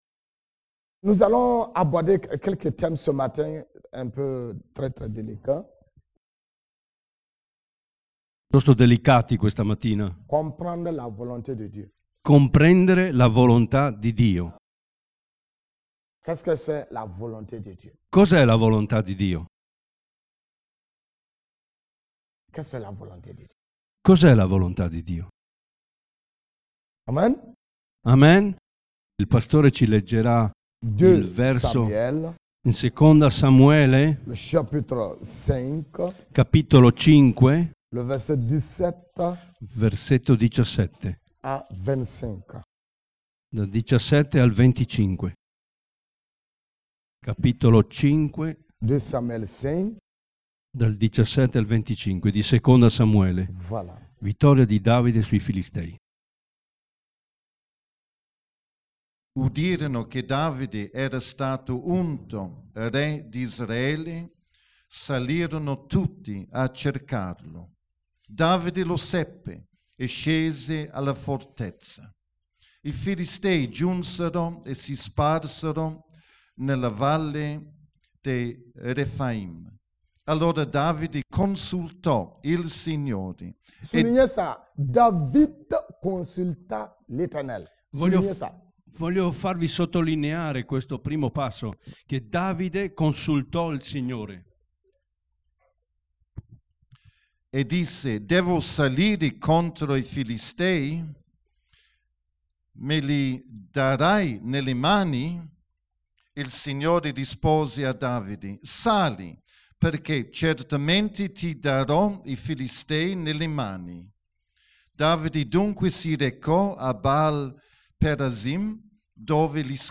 Predicazione 03 giugno 2012 - Figli fedeli